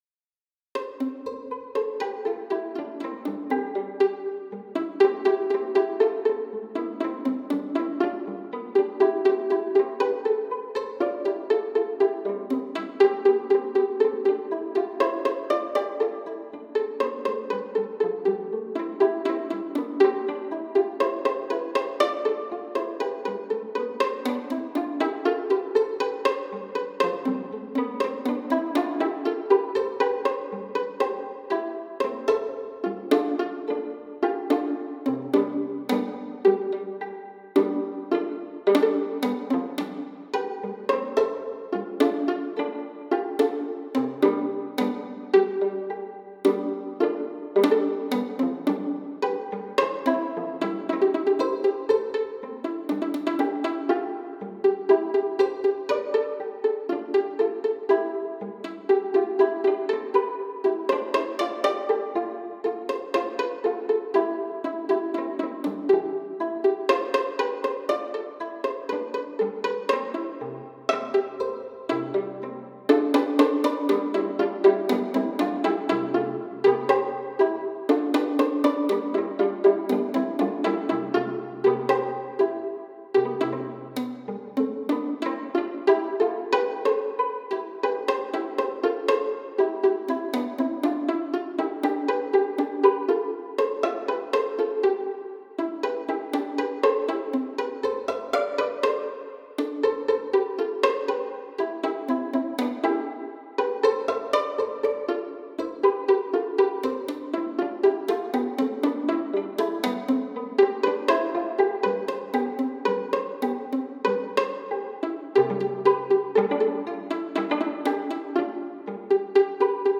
From the collection, number 105, treated wholly as a lively pizzcati "fest."
2 pages, circa 2' 15" - an MP3 demo is here: